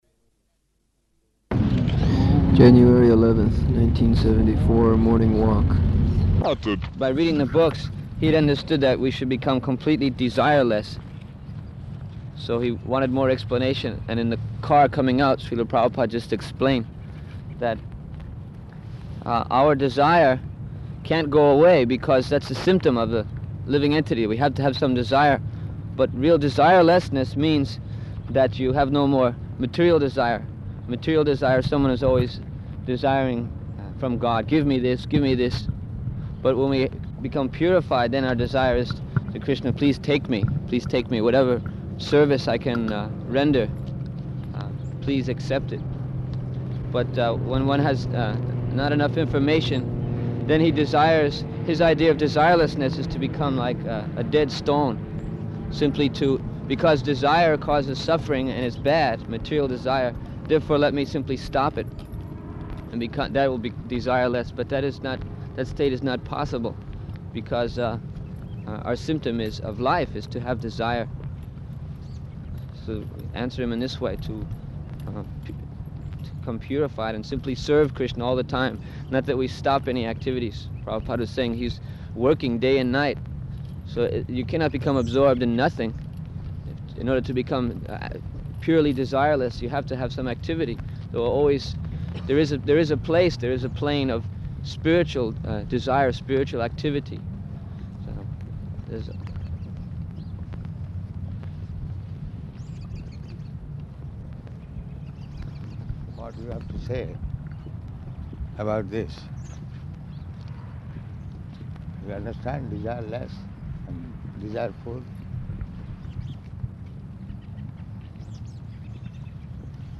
January 11th 1974 Location: Los Angeles Audio file